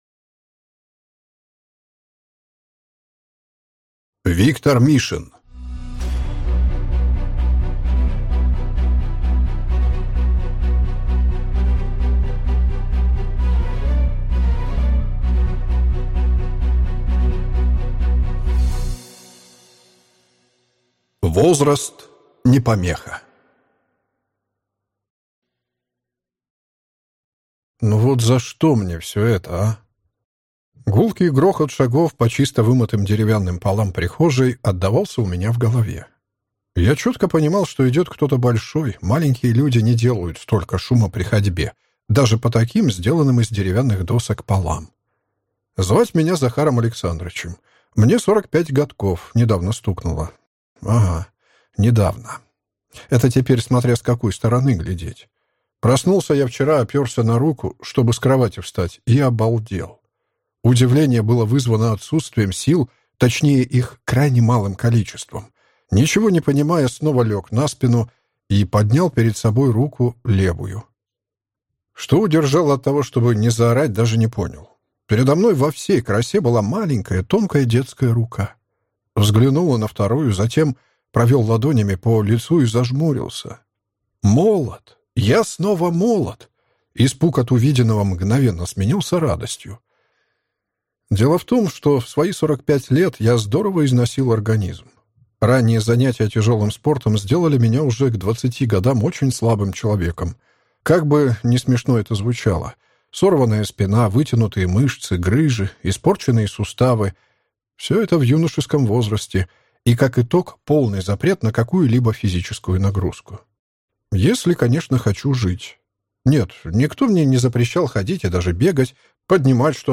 Аудиокнига Возраст не помеха | Библиотека аудиокниг